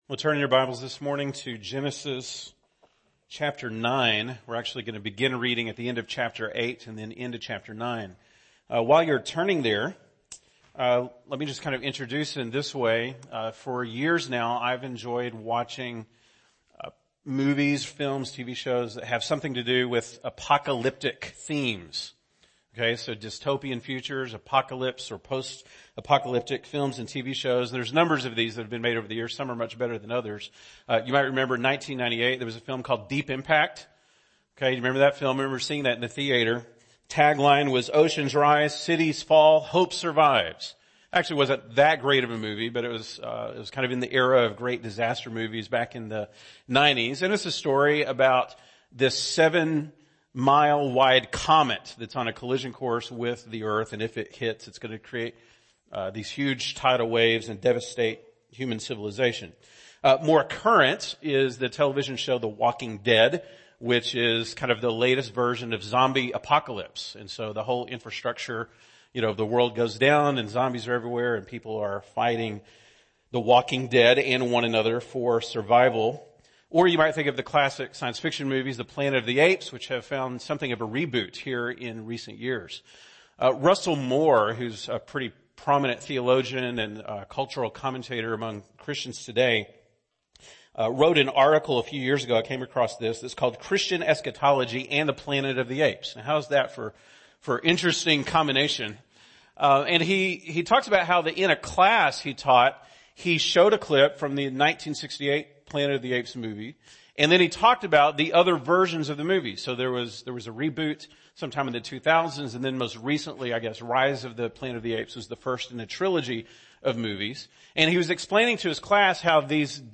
February 25, 2018 (Sunday Morning)